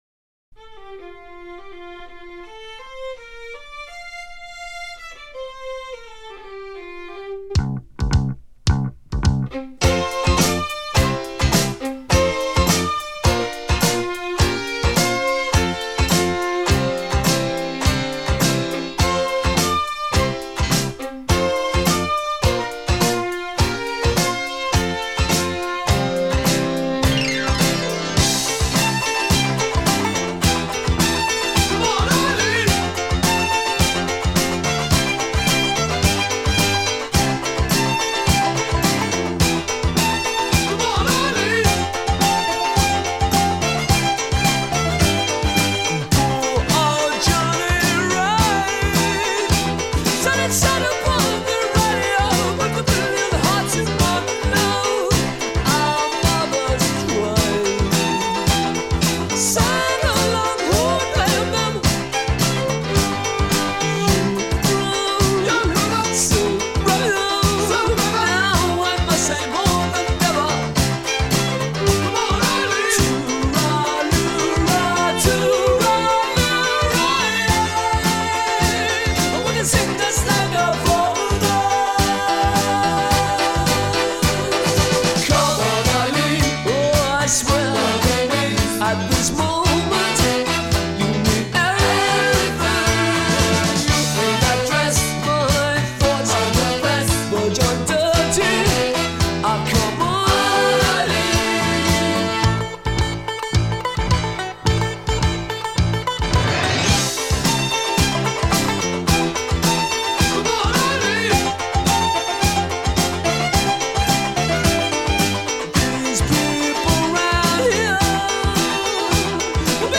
Vinyl rip